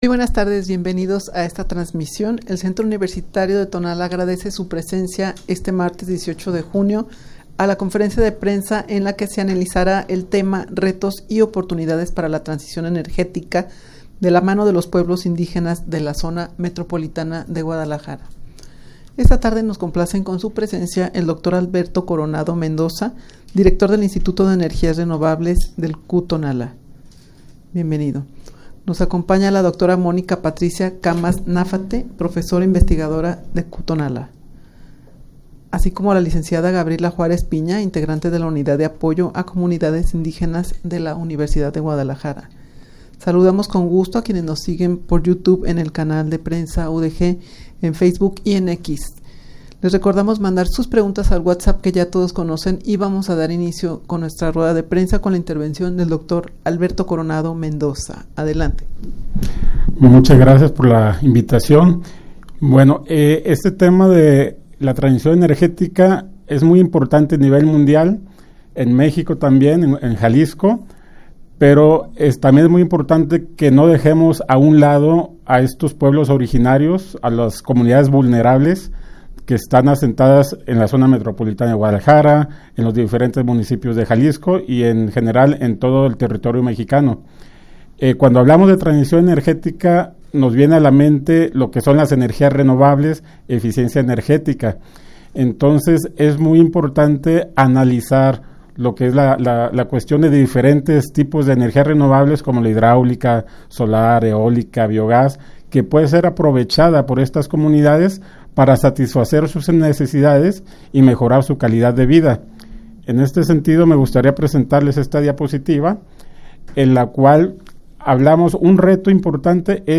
rueda-de-prensa-en-la-que-se-analizara-el-tema-retos-y-oportunidades-para-la-transicion-energetica-de-la-mano-de-los-pueblos-indigenas-de-la-zmg_1.mp3